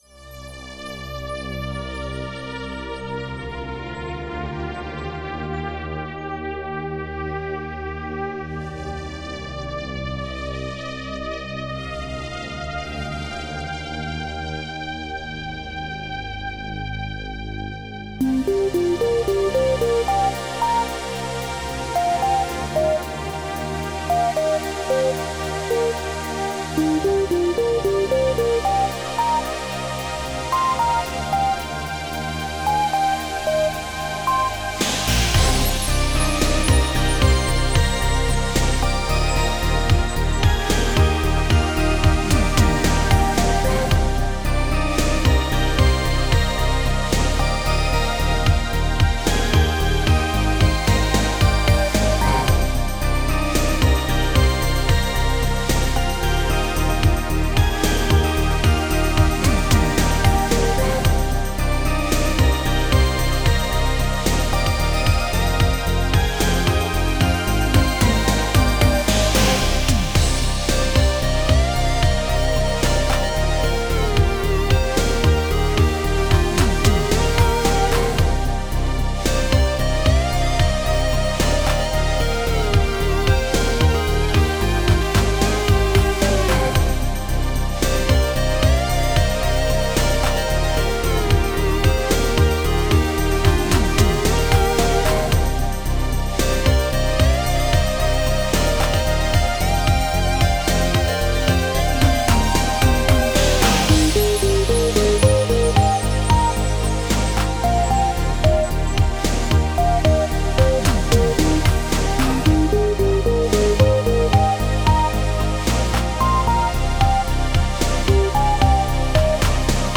Style: Synthpop Ballad
This calm synthpop ballad